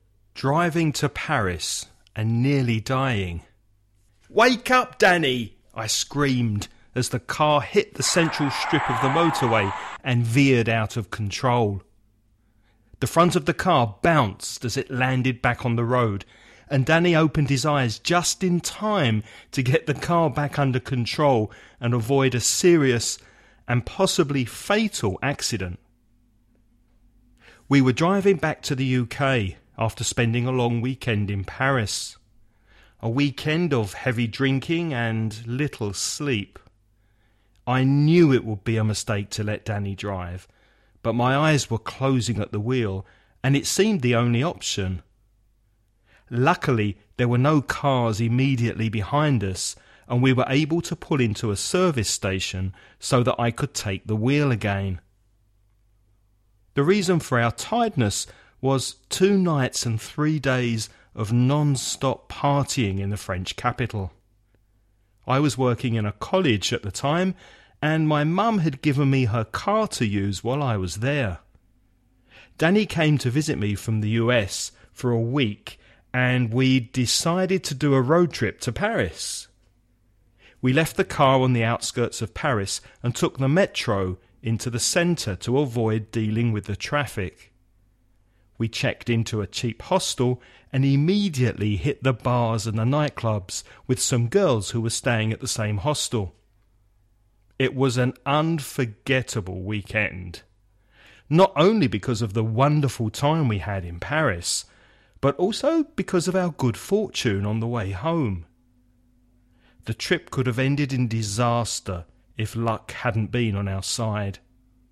Listening Practice
Before you listen about a man talking about an eventful long weekend in Paris, read the following questions.